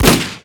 fire_bolter.ogg